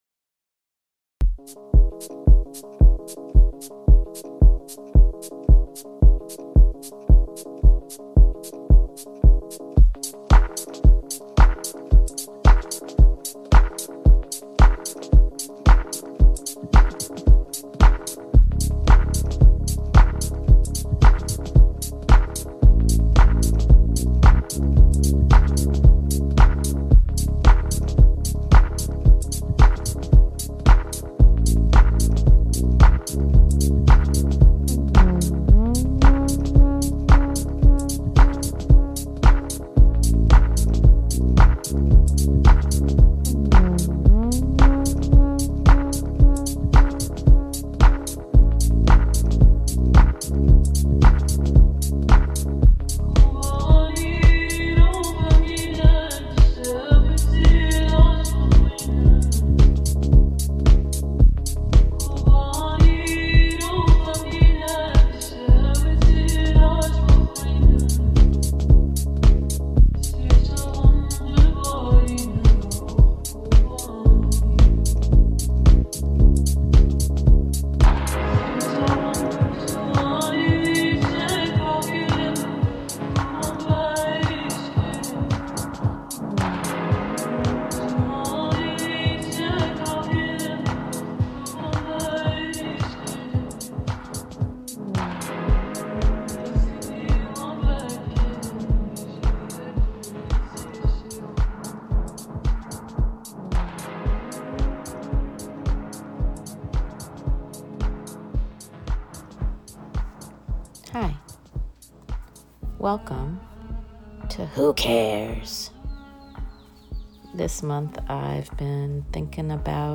is an interview-based segment that is a mini-deep dive into the world of care, what we care about...and maybe why.